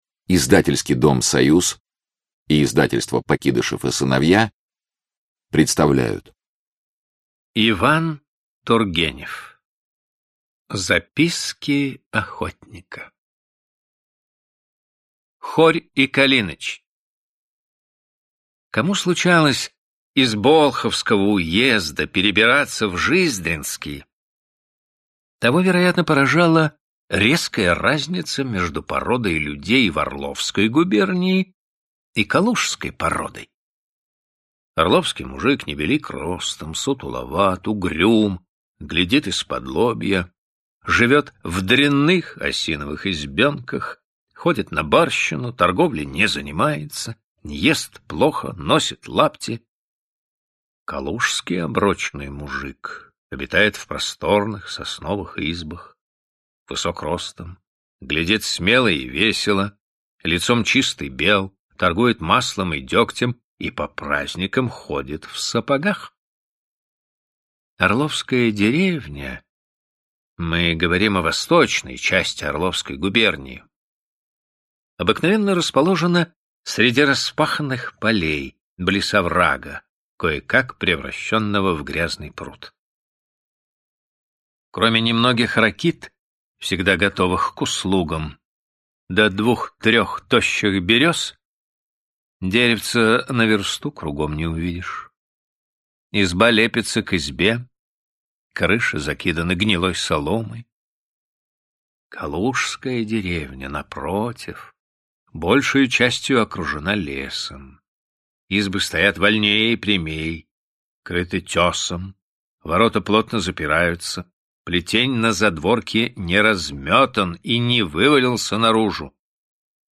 Аудиокнига Записки охотника | Библиотека аудиокниг